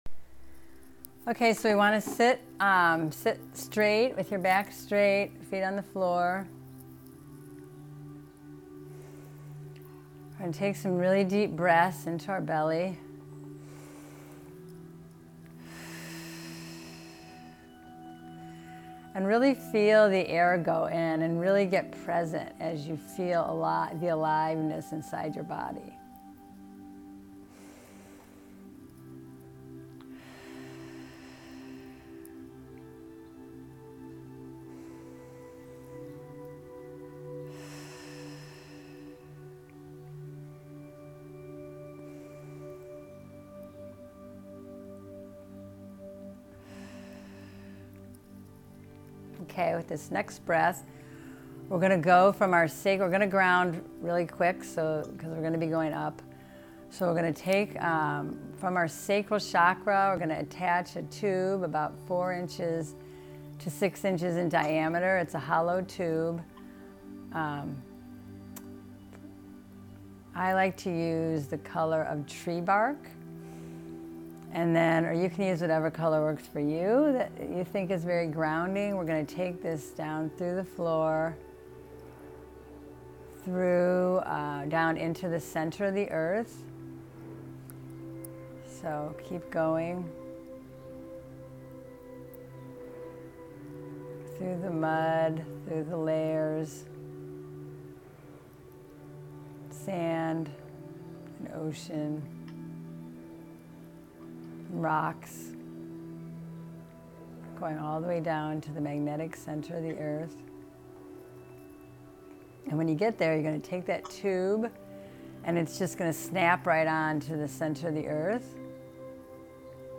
Meditation+to+connect+2ur+soul-Music+added.mp3